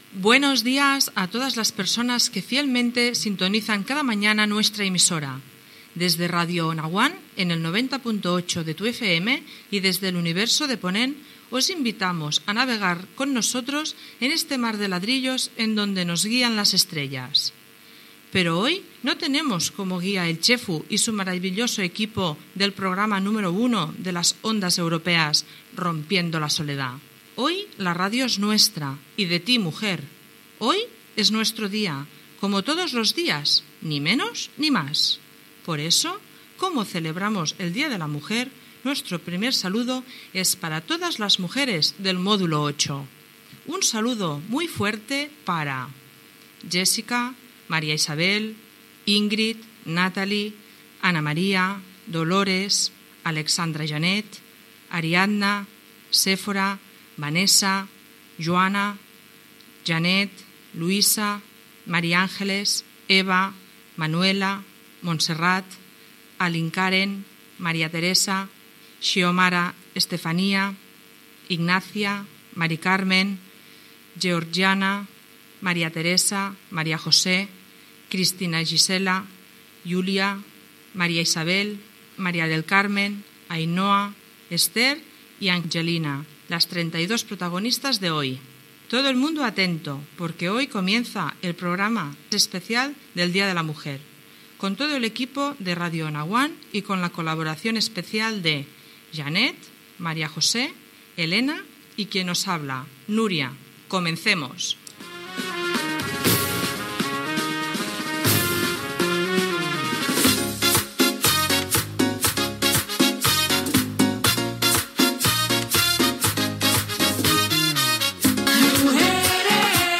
FM
Centre Penitenciari de Ponent.